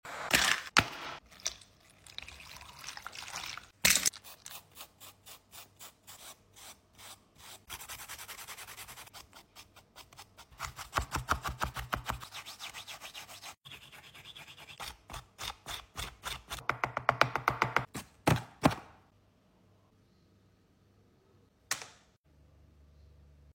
ASMR Underwater Gold Mining! sound effects free download